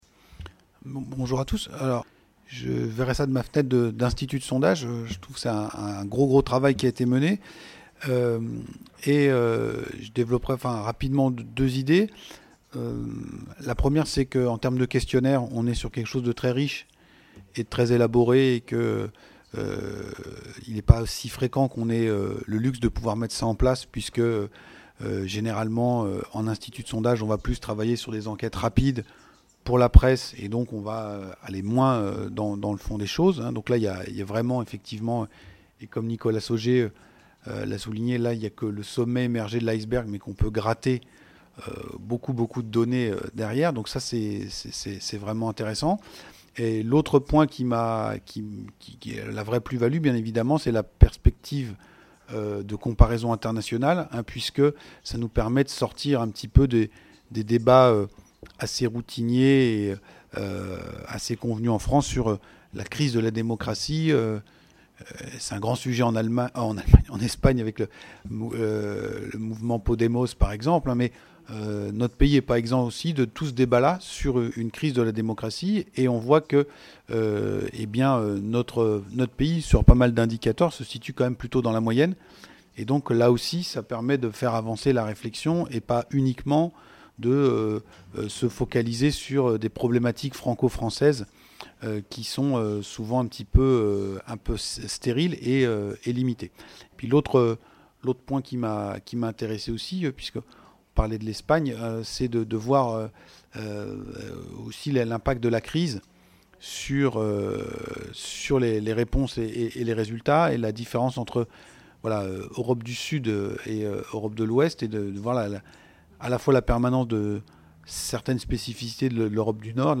ESS Topline results presentation - Europeans and democracy - JEROME FOURQUET | Canal U